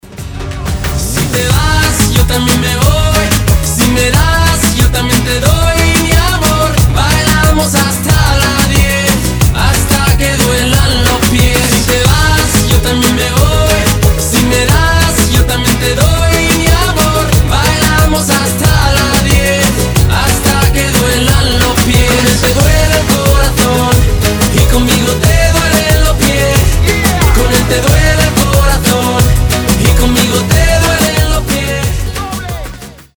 • Качество: 320, Stereo
ритмичные
заводные
испанские
латина
реггетон